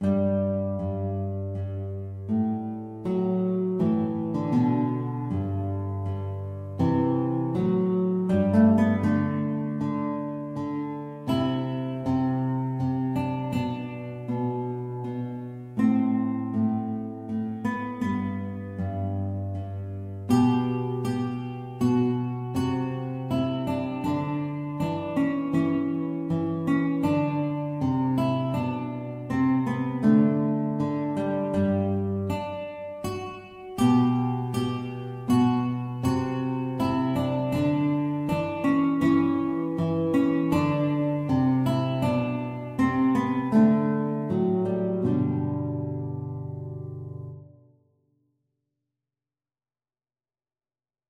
Classical Handel, George Frideric Largo from Xerxes Guitar version
G major (Sounding Pitch) (View more G major Music for Guitar )
Andante grandioso
3/4 (View more 3/4 Music)
Guitar  (View more Intermediate Guitar Music)
Classical (View more Classical Guitar Music)
handel_xerxes_GUIT.mp3